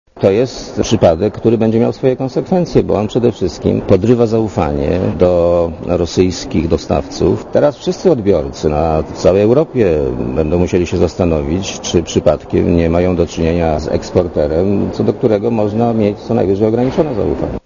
Na razie nie ma groźby, że zabraknie nam gazu - mówi premier Leszek Miller.
Komentarz audio